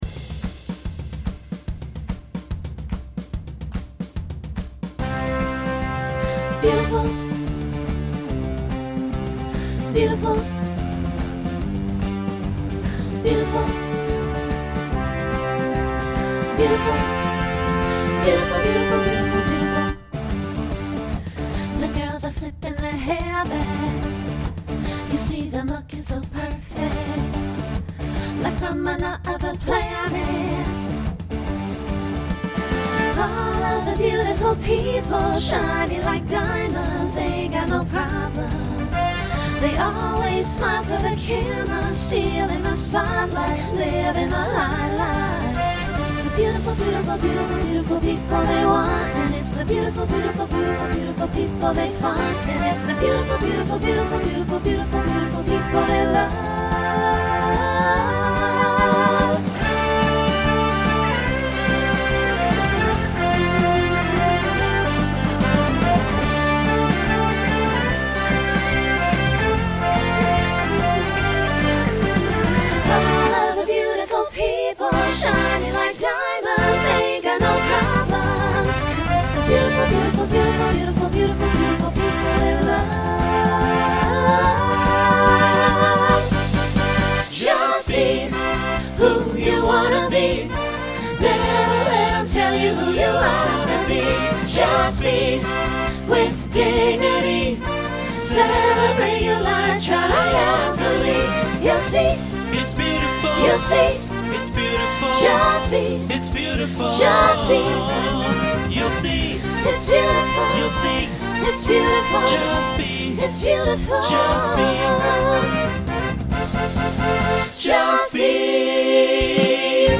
Mostly SSA with a little SATB at the end.
Broadway/Film , Pop/Dance
Voicing SSA